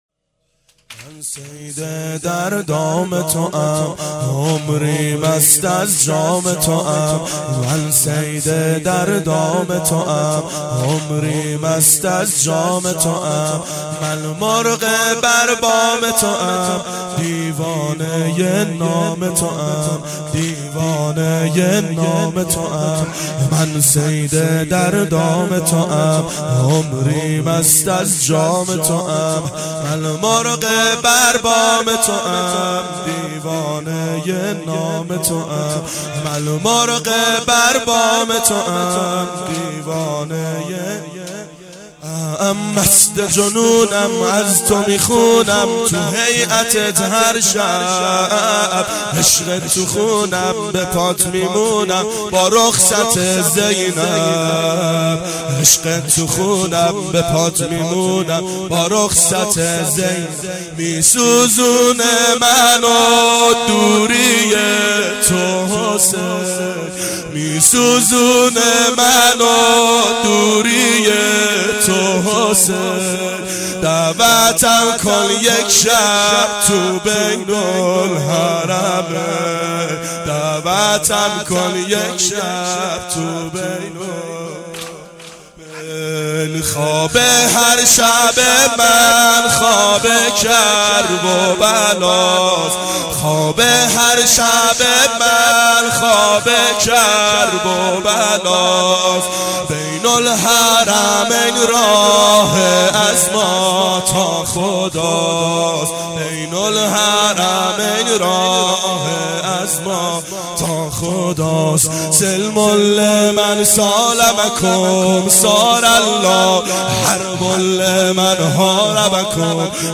• ظهر اربعین سال 1389 محفل شیفتگان حضرت رقیه سلام الله علیها